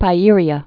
(pī-îrē-ə)